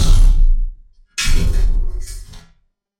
Robot Walking
A heavy robot walking with servo whirs, hydraulic hisses, and metallic footstep impacts
robot-walking.mp3